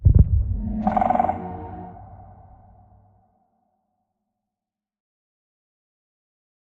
Minecraft Version Minecraft Version snapshot Latest Release | Latest Snapshot snapshot / assets / minecraft / sounds / mob / warden / nearby_closest_2.ogg Compare With Compare With Latest Release | Latest Snapshot